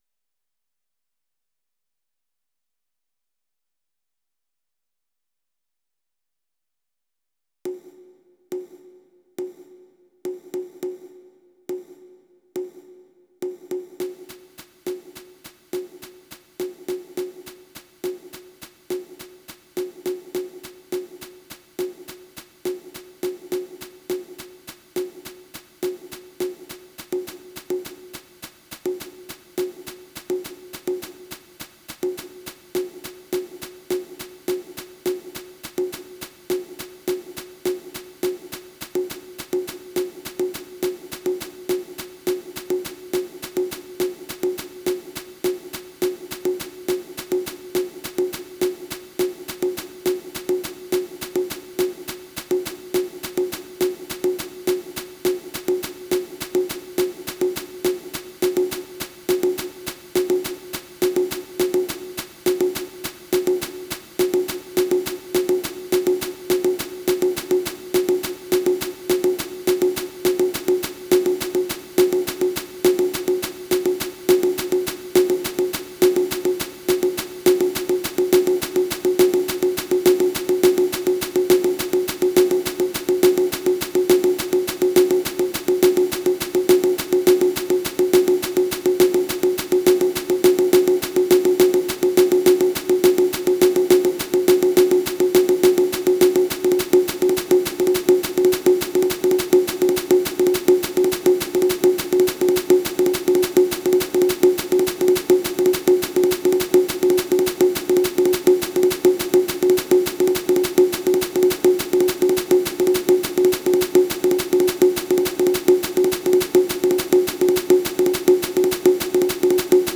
音源は全てVSC-88です。